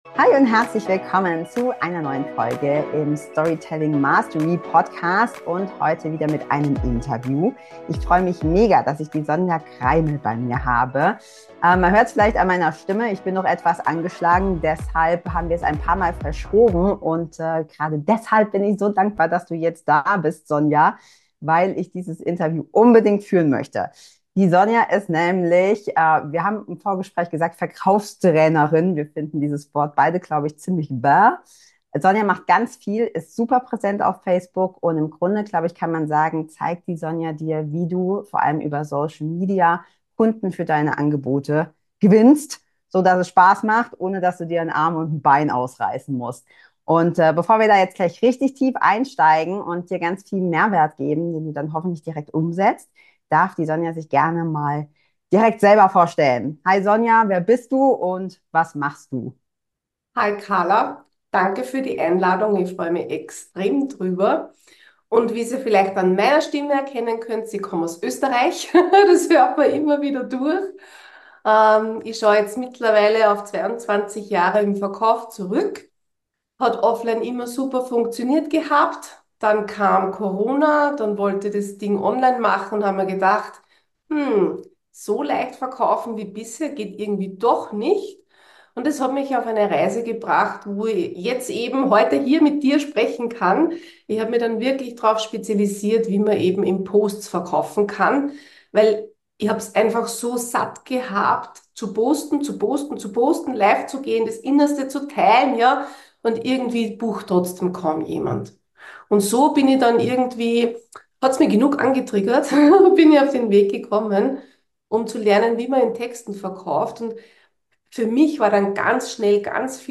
#39 Mehr als nur Likes: Die Geheimnisse des Verkaufs über Social Media, die du kennen musst! Interview